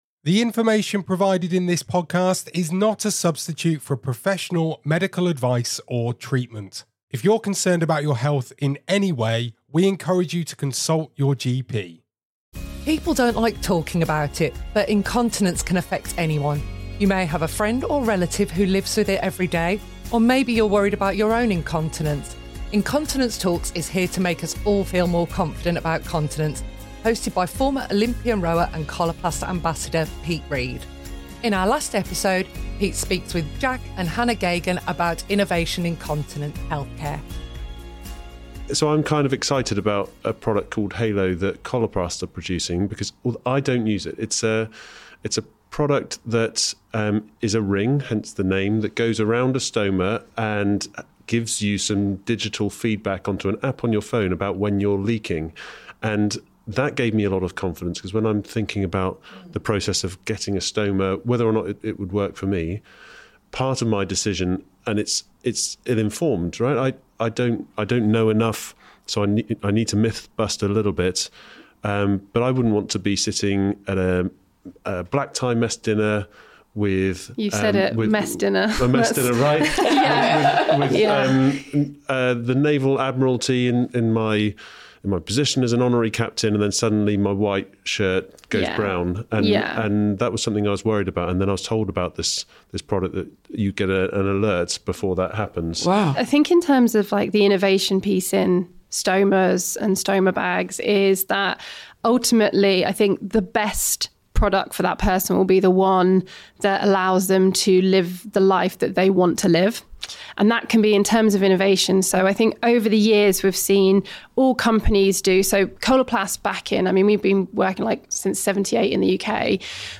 Retired British Olympic rower, Pete Reed speaks